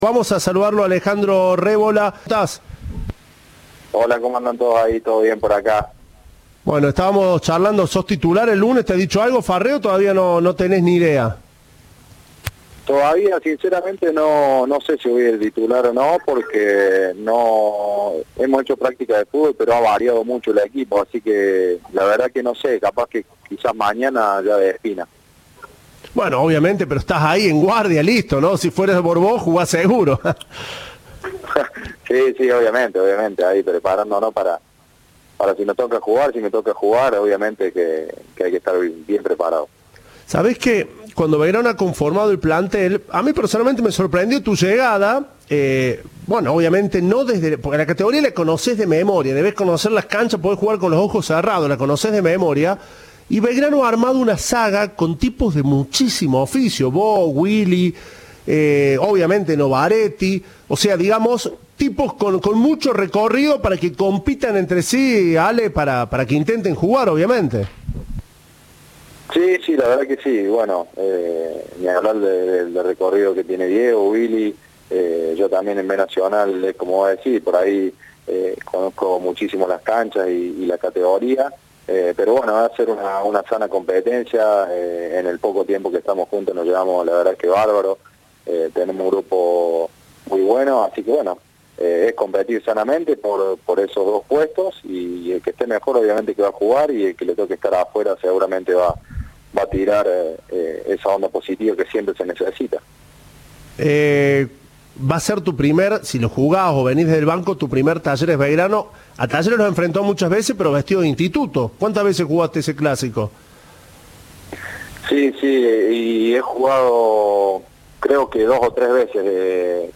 Entrevista de "Tiempo de Juego".